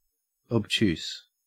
Ääntäminen
Synonyymit blunt (arkikielessä) thick dull dense dim muffled obtuse-angled deadened dim-witted pointless purblind Ääntäminen GA AU UK : IPA : /əbˈtjuːs/ IPA : /-ˈtʃuːs/ GA : IPA : /əbˈt(j)us/ IPA : /ɑb-/